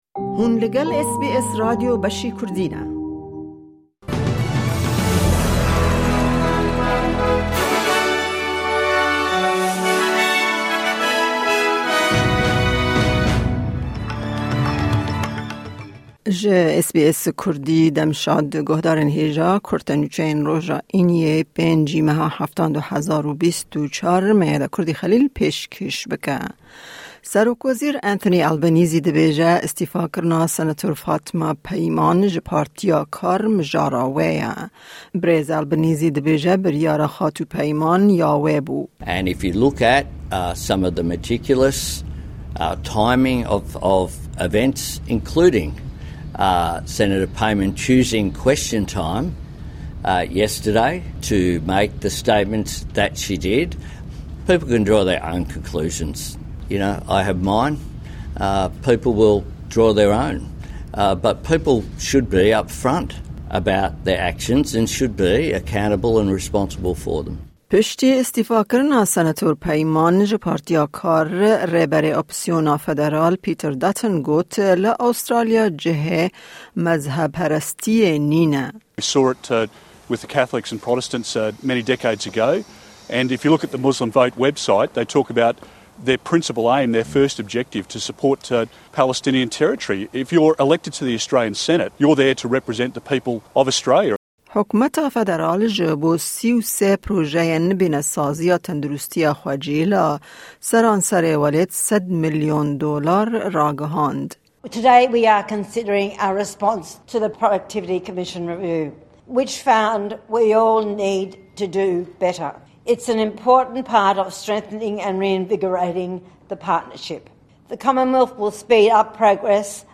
Kurte Nûçeyên roja Înî 5î tîrmeha 2024